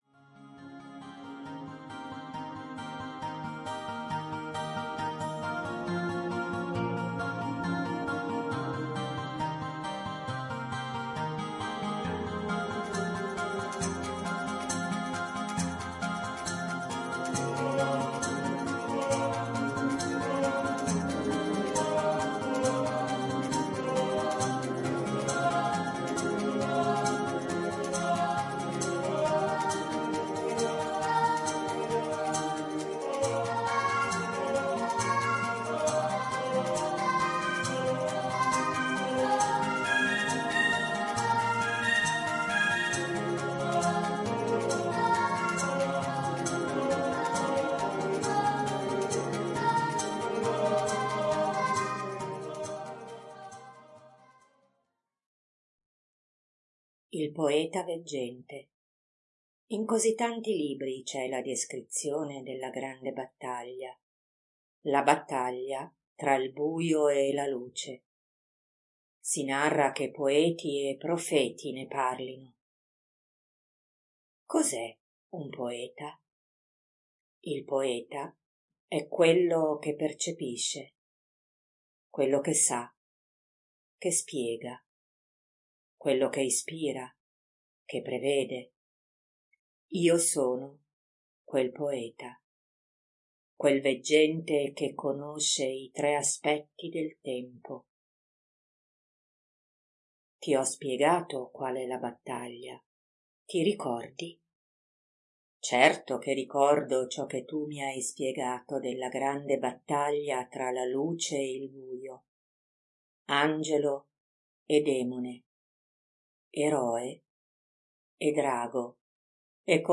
Grazie - Audio Libro